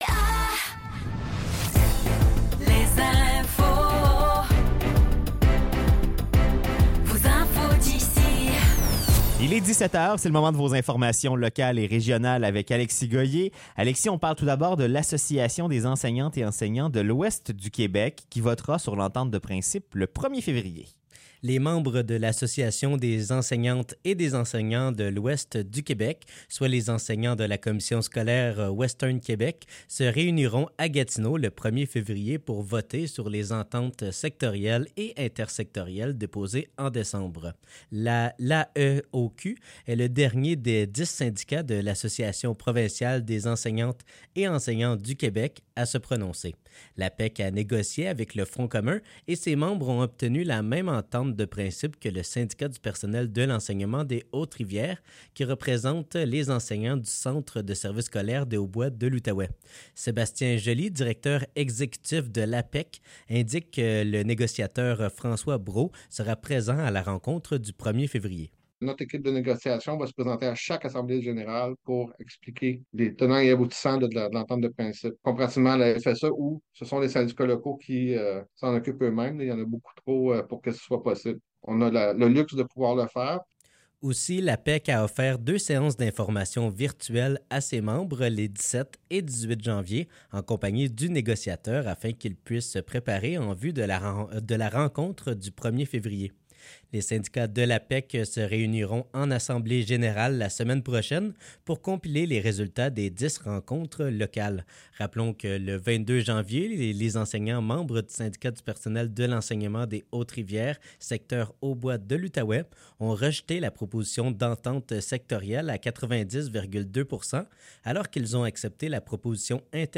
Nouvelles locales - 30 janvier 2024 - 17 h